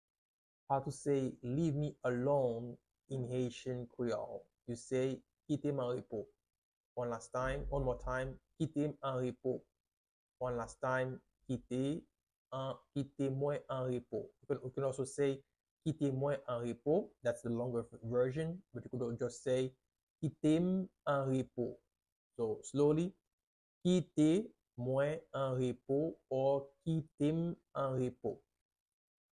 How to say “Leave Me Alone” in Haitian Creole - Kite mwen anrepo pronunciation by a native Haitian Teacher
“Kite mwen anrepo” Pronunciation in Haitian Creole by a native Haitian can be heard in the audio here or in the video below:
How-to-say-Leave-Me-Alone-in-Haitian-Creole-Kite-mwen-anrepo-pronunciation-by-a-native-Haitian-Teacher.mp3